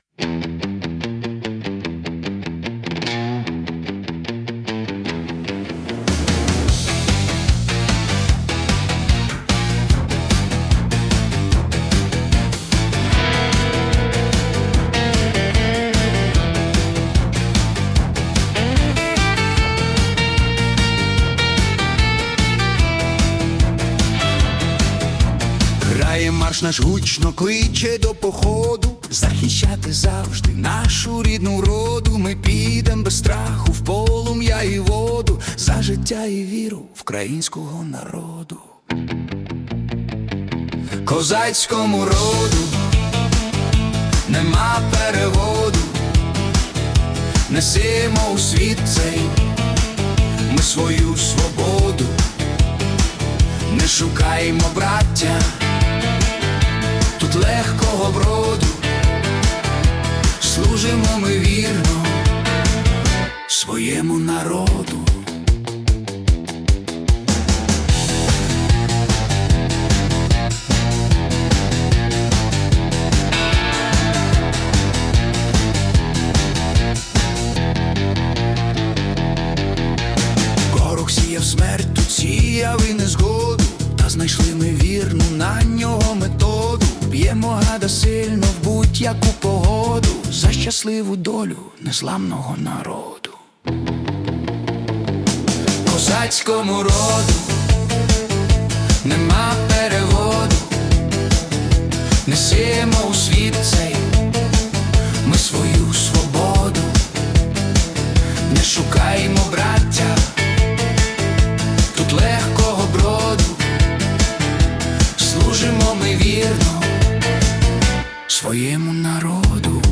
🎵 Жанр: Energetic Synth-Pop / Patriotic